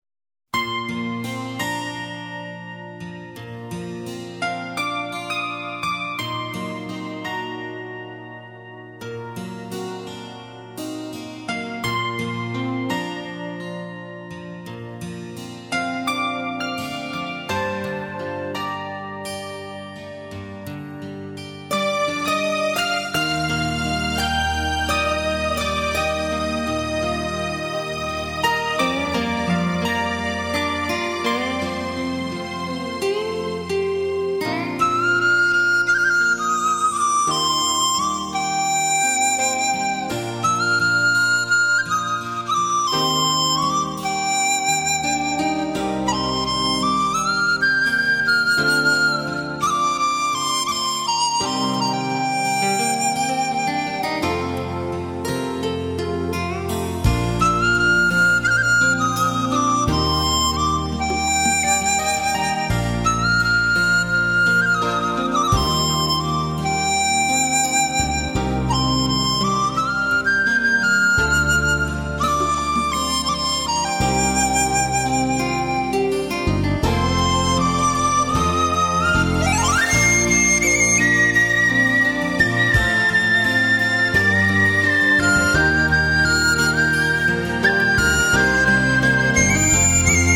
Kínai népzene